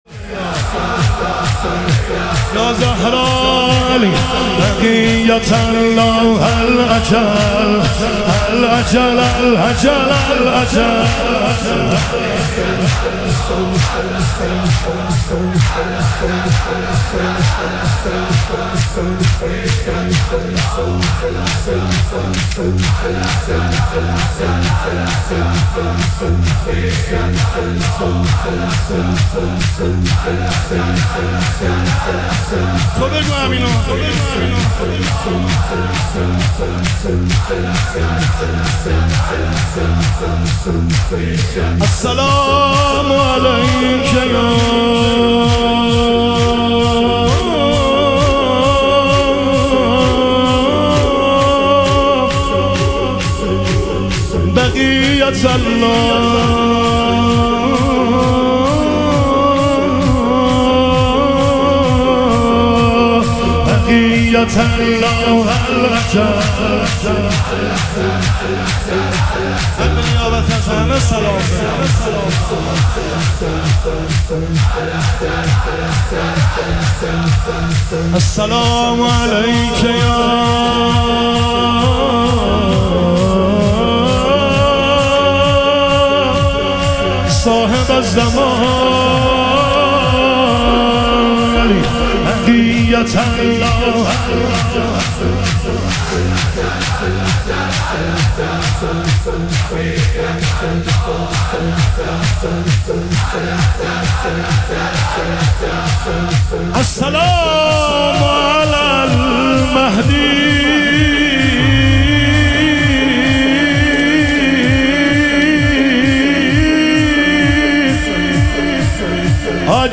شهادت حضرت خدیجه علیها سلام - شور